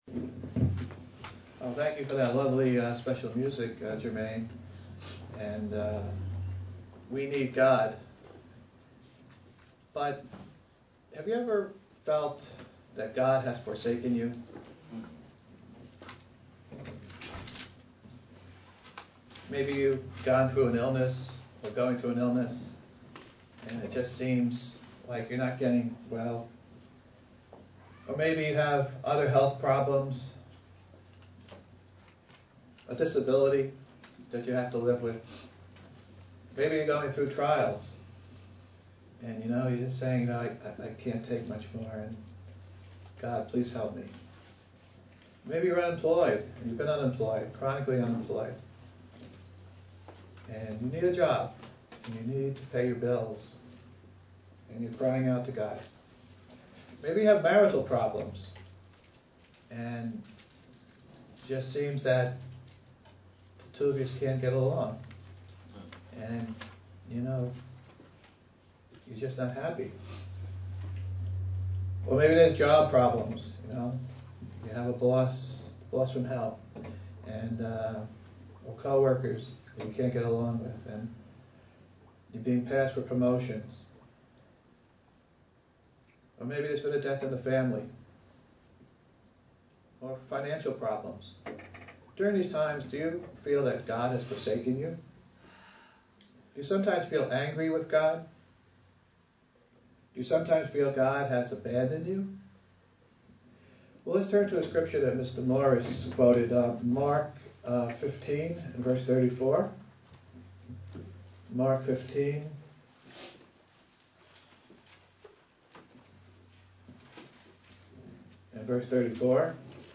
Print UCG Sermon Studying the bible?
Given in New York City, NY